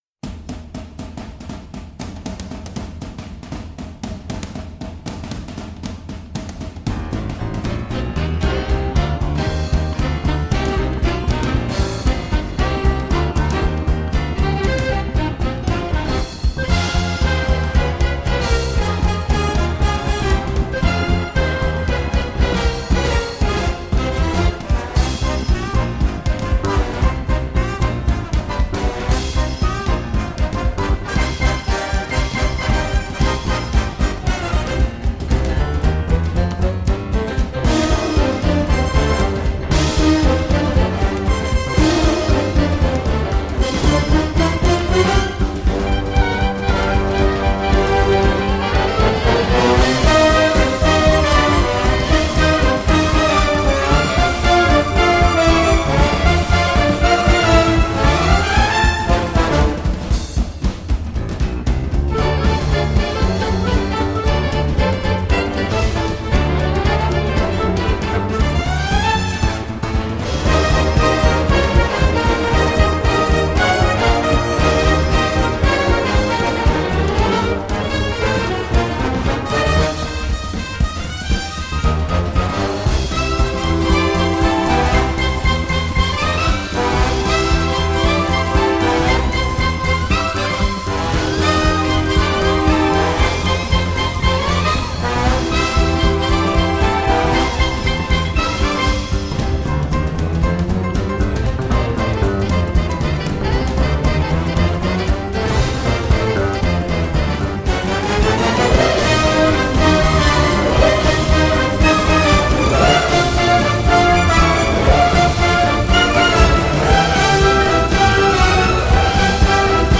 Du jazz à gogo, de la nostalgie et du sport.